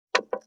567肉切りナイフ,まな板の上,
効果音厨房/台所/レストラン/kitchen食器食材
効果音